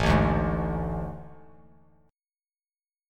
AM7sus4 chord